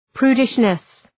Προφορά
{‘pru:dıʃnıs}